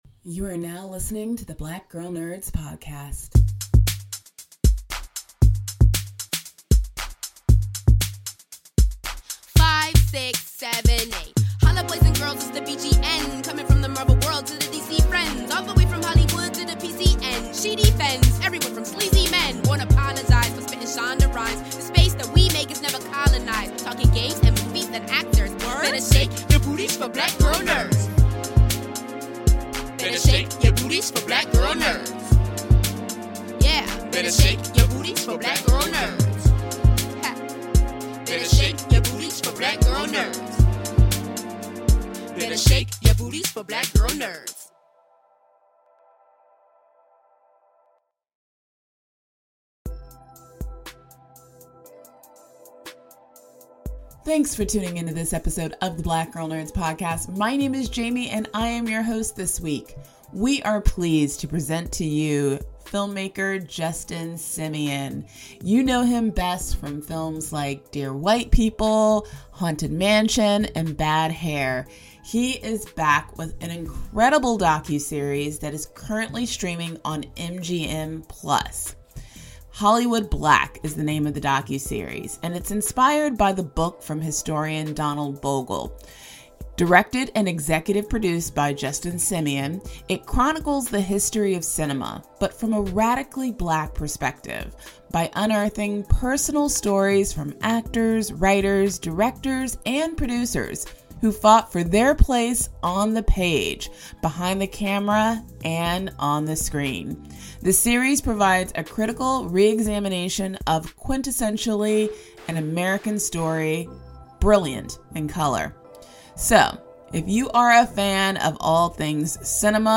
In this week's episode of the Black Girl Nerds podcast, we interview director and executive producer Justin Simien of the new docuseries Hollywood Black.